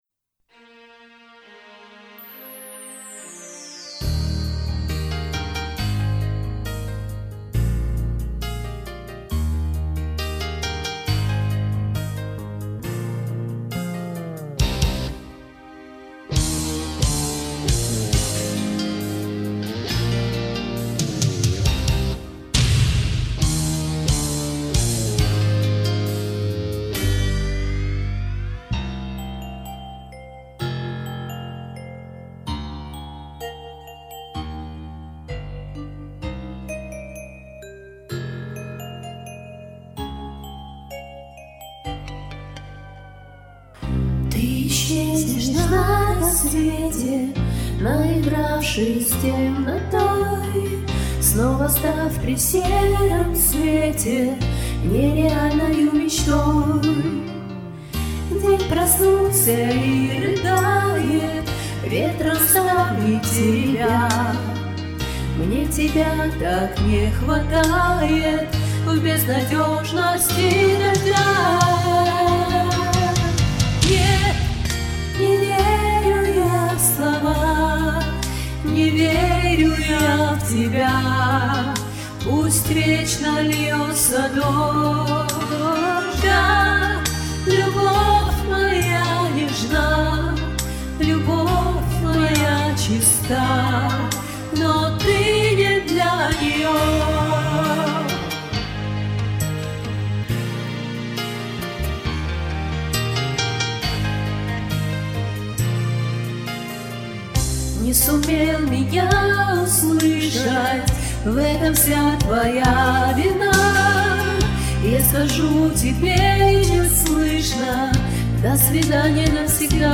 Комментарий инициатора: Комментарий соперника: попса из 90-х